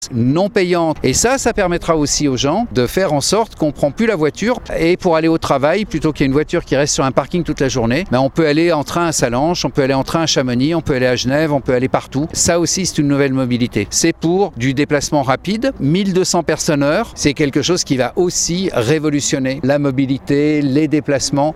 Il existe même un service de navettes, comme l’explique Jean-Marc Peillex le maire de Saint-Gervais.